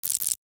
NOTIFICATION_Subtle_14_mono.wav